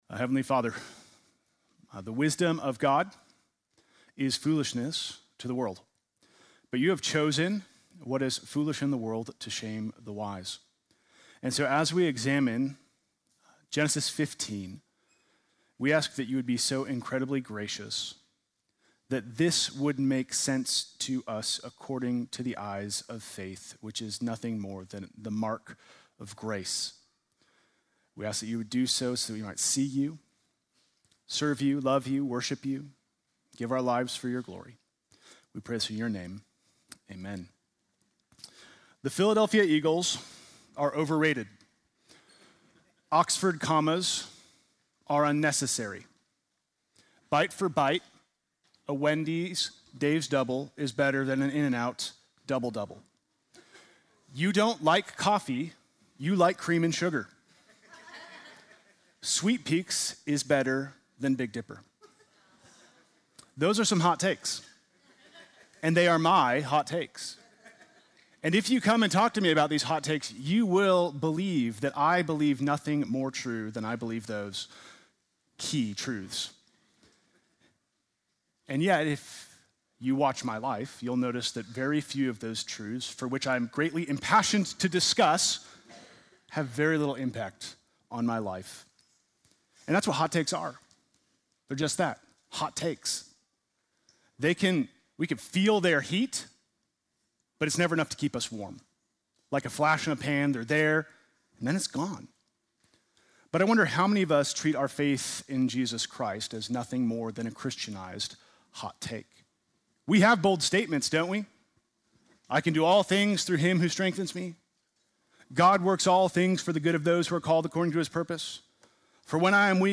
Sunday morning message February 15